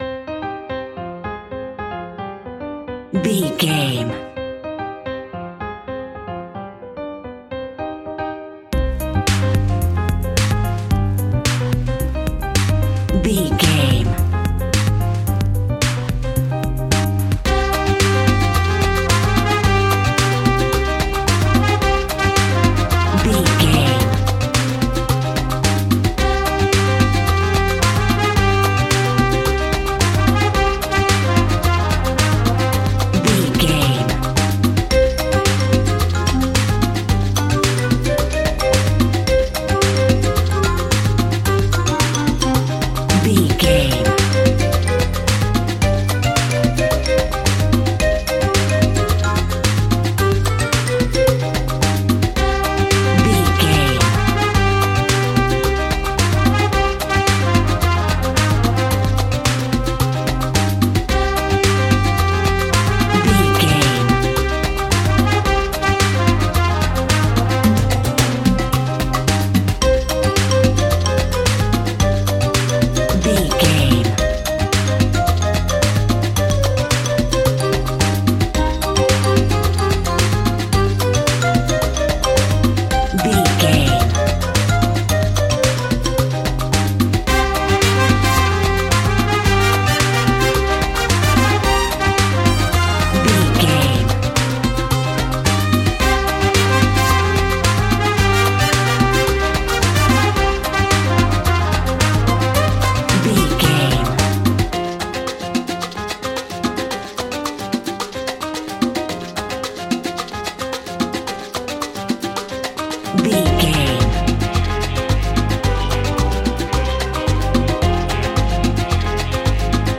Aeolian/Minor
percussion
piano
synthesiser
silly
circus
goofy
comical
cheerful
perky
Light hearted
quirky